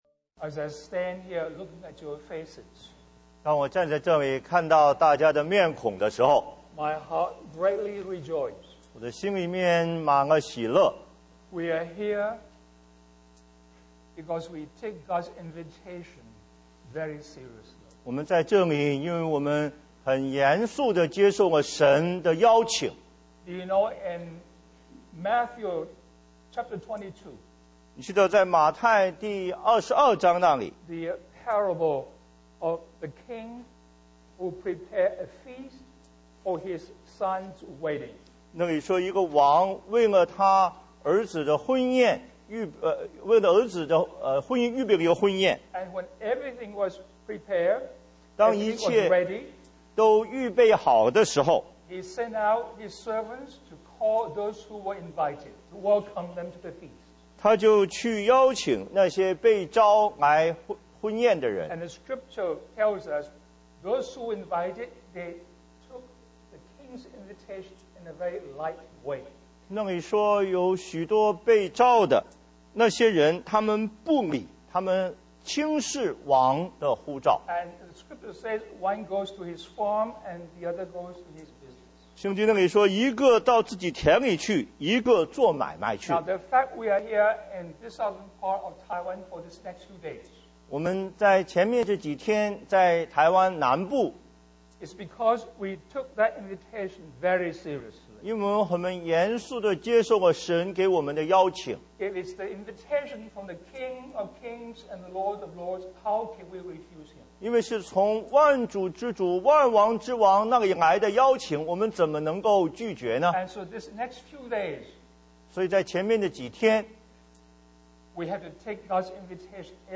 15th Conference On Service: Opening Remarks